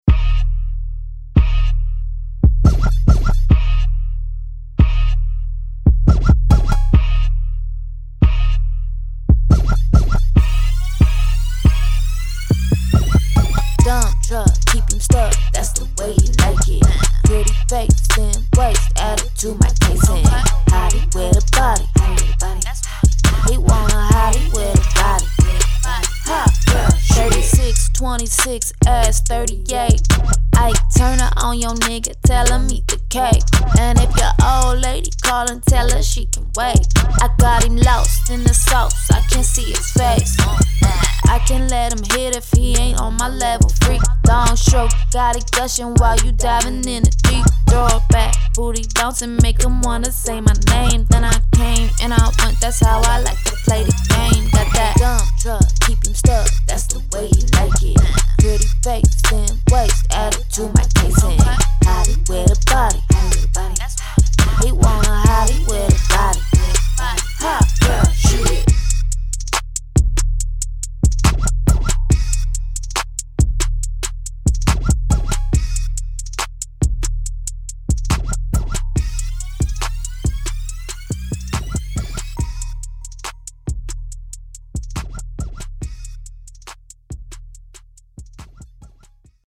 Hip Hop
Eb Minor